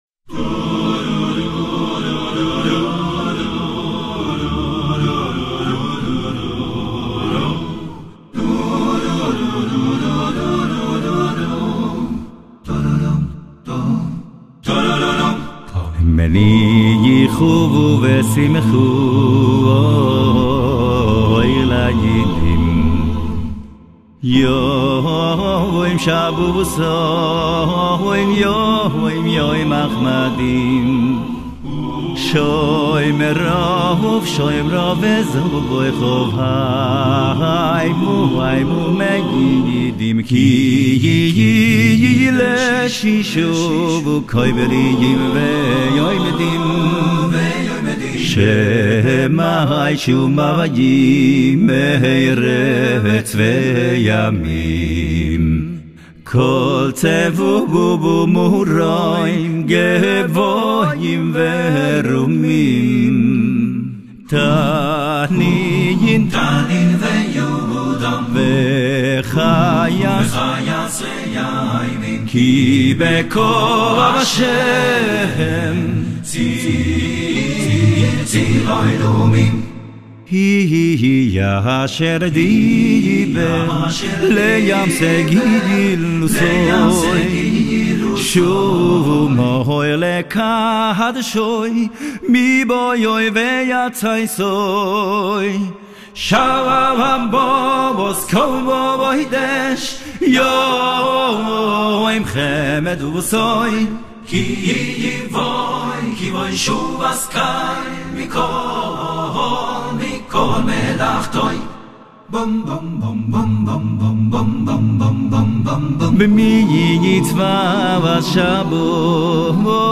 מנוחה ושמחה - ווקאלי